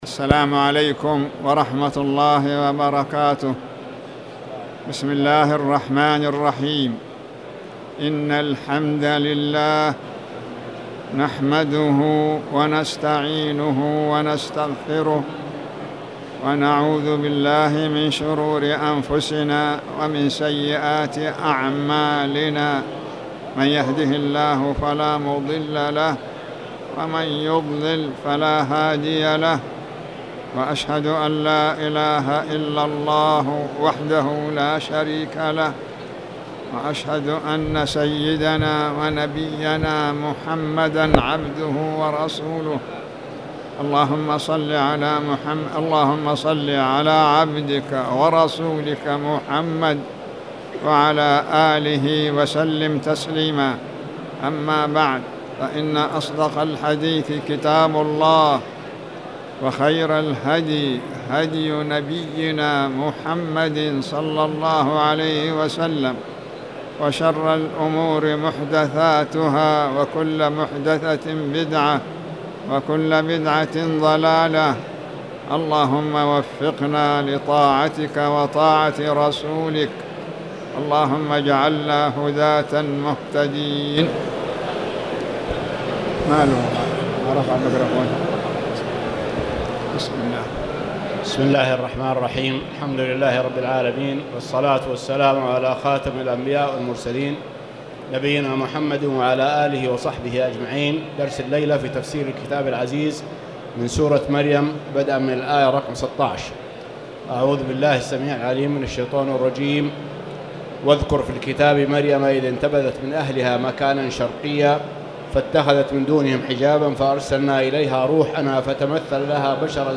تاريخ النشر ٢٠ ربيع الأول ١٤٣٨ هـ المكان: المسجد الحرام الشيخ